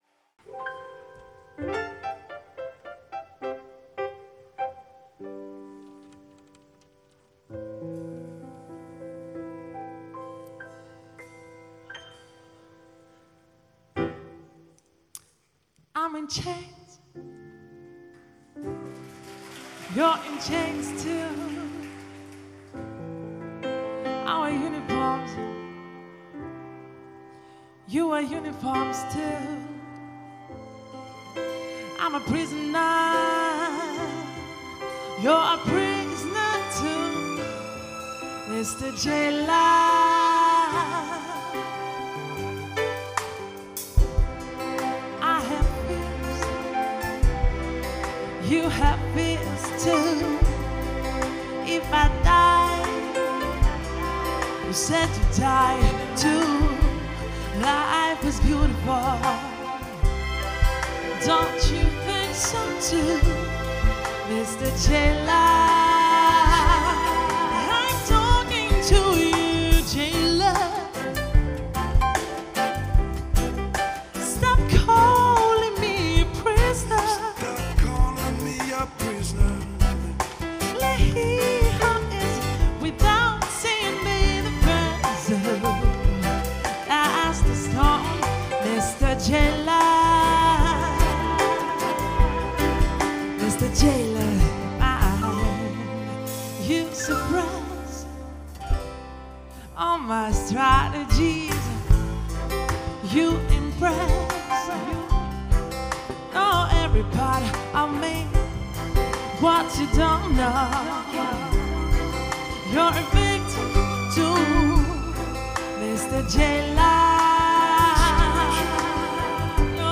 vocals, keyboards
bass guitar
flute